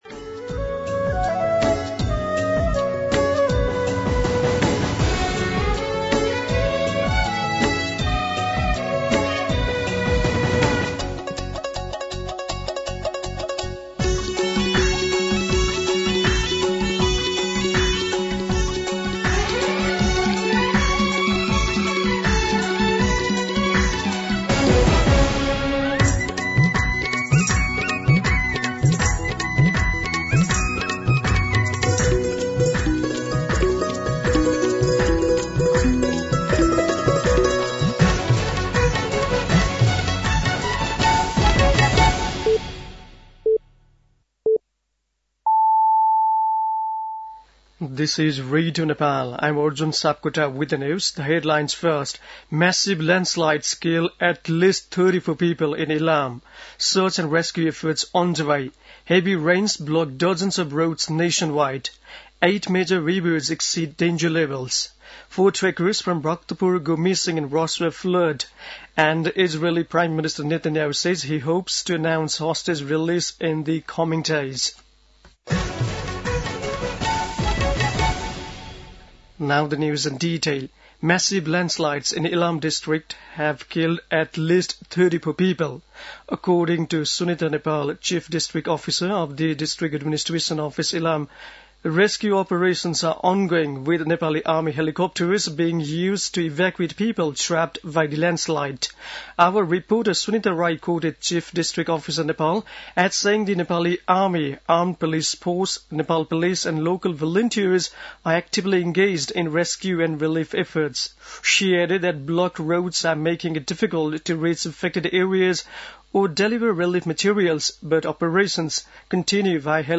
दिउँसो २ बजेको अङ्ग्रेजी समाचार : १९ असोज , २०८२
2pm-English-News-19.mp3